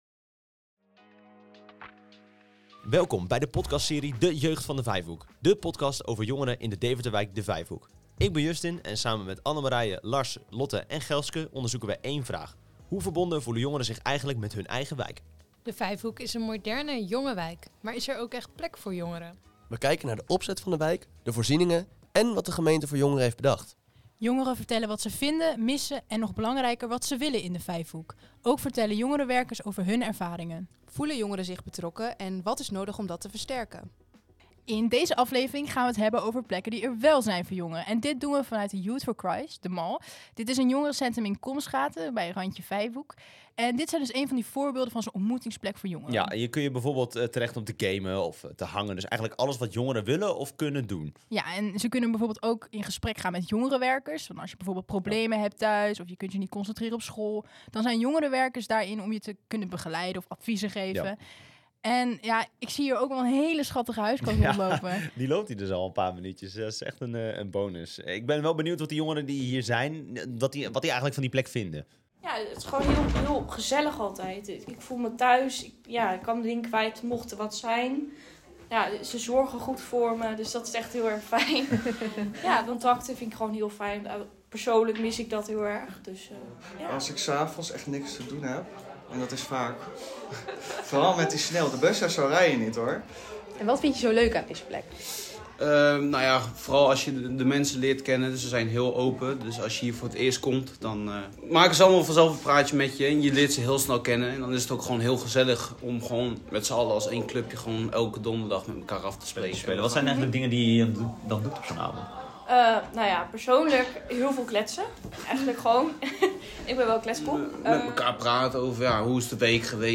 Vanuit jongerencentrum The Mall in Colmschate zien we hoe een goede ontmoetingsplek werkt, inclusief huiskat en een luisterend oor.
Er is echter goed nieuws: The Mall heeft concrete plannen voor een nieuwe locatie midden in de wijk. We spreken met de gemeente en jongerenwerkers over dit initiatief en vragen de jongeren zelf of zij hier straks ook echt gaan hangen.